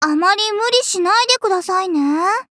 文件 文件历史 文件用途 全域文件用途 Ja_Fifi_fw_02.ogg （Ogg Vorbis声音文件，长度2.5秒，107 kbps，文件大小：33 KB） 源地址:游戏语音 文件历史 点击某个日期/时间查看对应时刻的文件。 日期/时间 缩略图 大小 用户 备注 当前 2018年5月25日 (五) 02:12 2.5秒 （33 KB） 地下城与勇士  （ 留言 | 贡献 ） 分类:祈求者比比 分类:地下城与勇士 源地址:游戏语音 您不可以覆盖此文件。